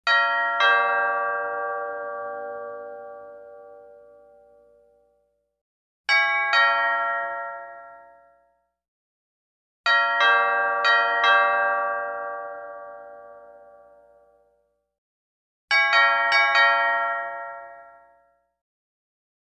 Звуки звонка в дверь
Дверной колокол в роскошном особняке (2) n5.